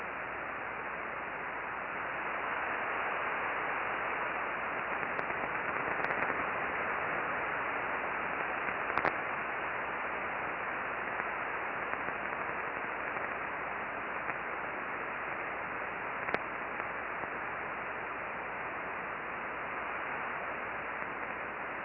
Solar Radio Observations for 07 June 2012
Click on the above spectrogram to listen to audio associated with the solar burst at 0149.